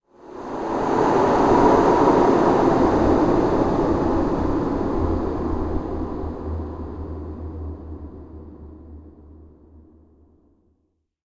windStart.ogg